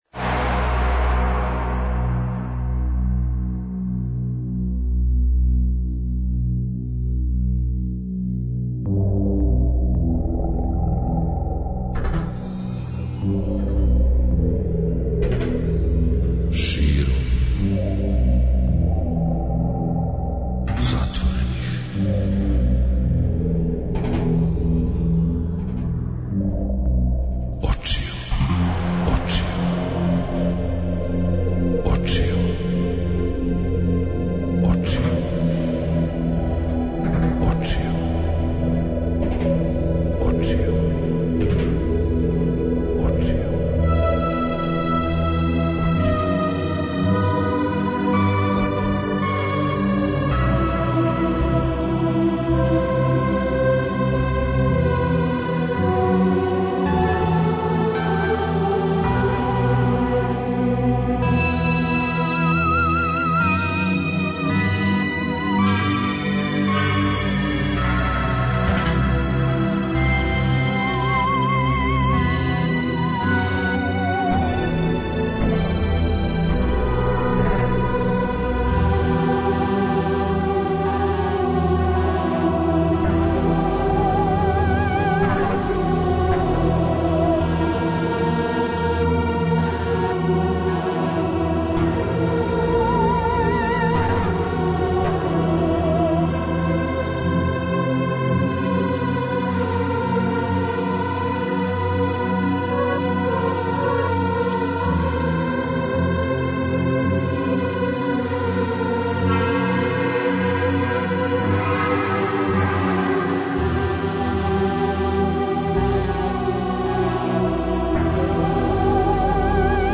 Ноћ недеља на понедељак, још једно окупљање духовних илегалки и илегалаца на том дивном месту, на таласу Београда 202 у емисији Још овај пут.